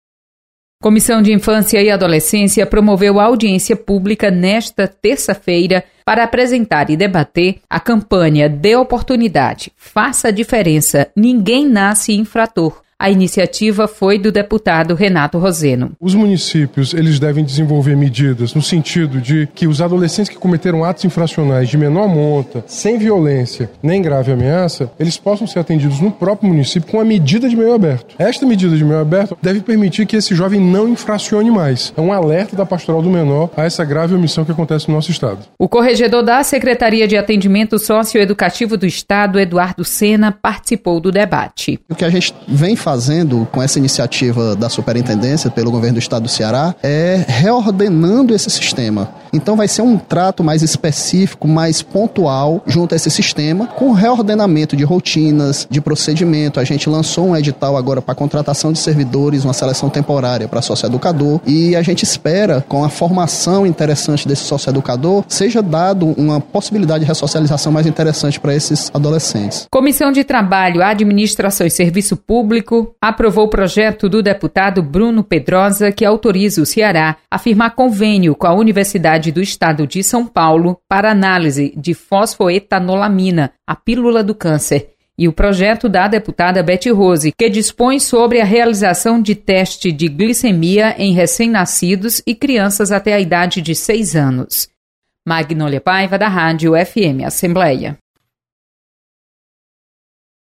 Acompanhe resumo da comissões técnicas permanentes da Assembleia Legislativa. Repórter